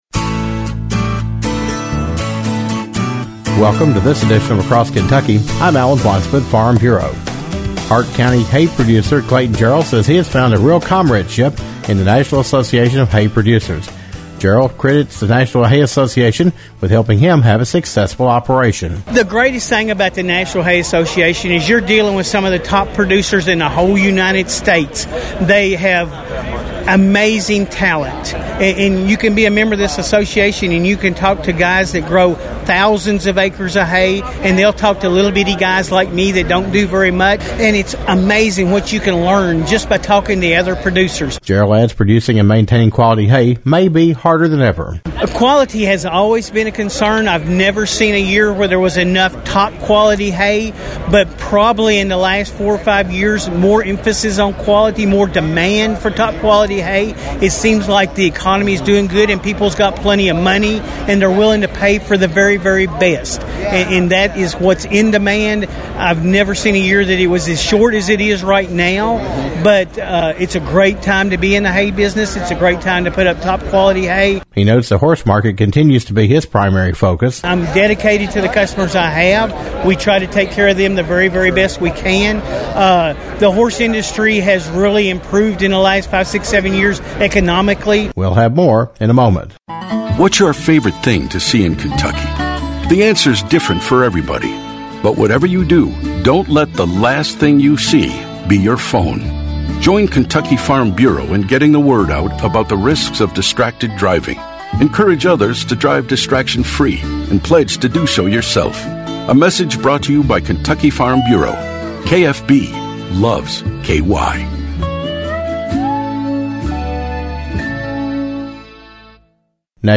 We continue our conversation